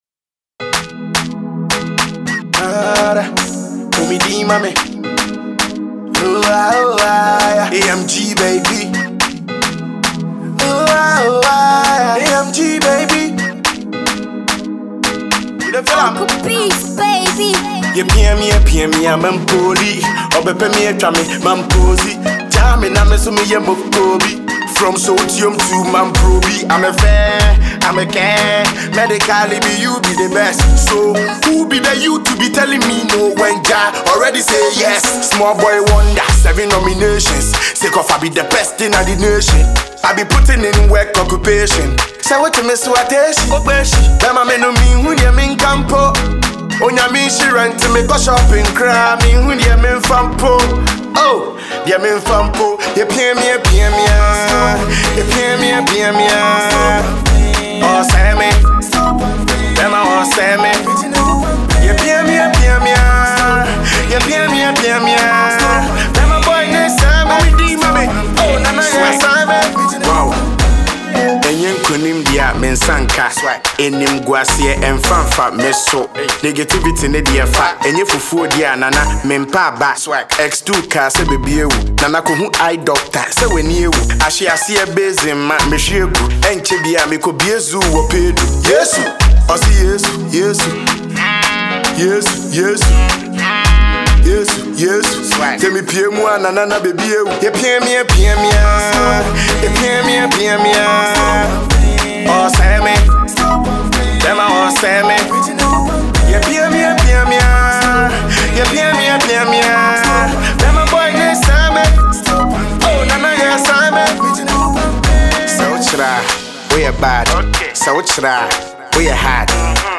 Ghana Music
rapper and singer